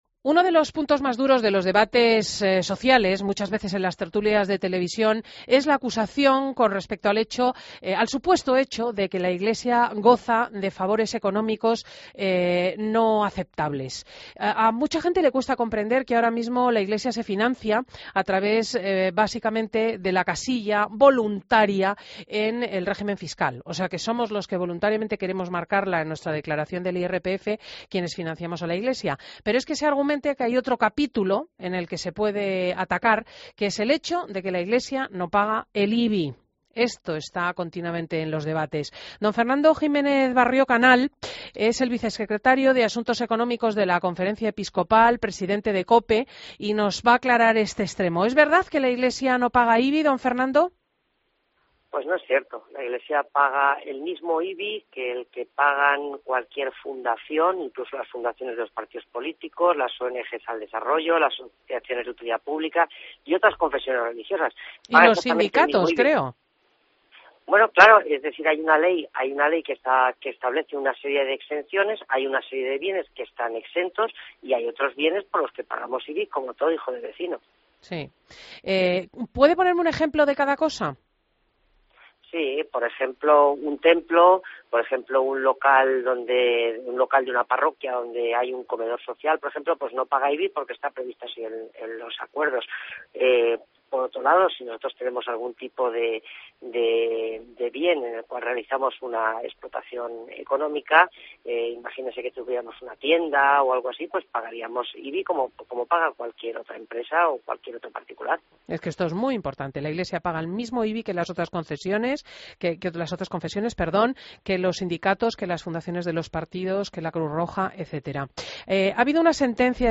Entrevistas en Fin de Semana